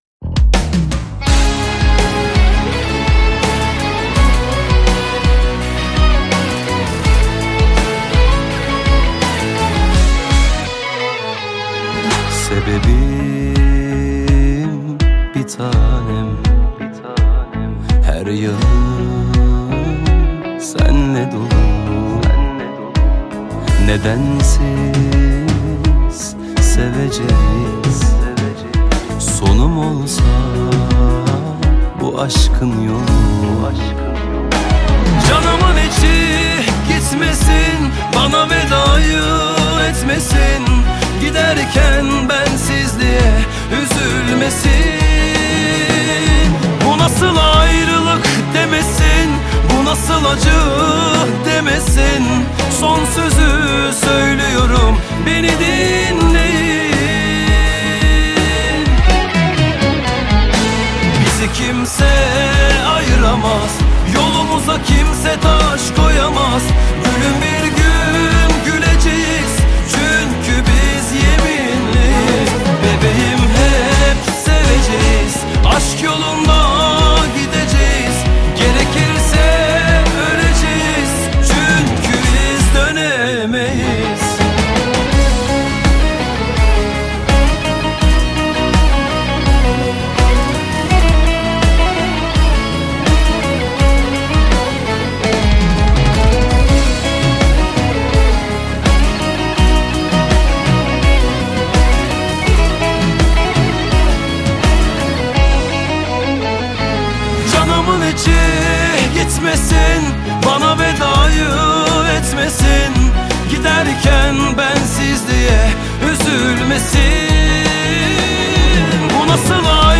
تک اهنگ ترکی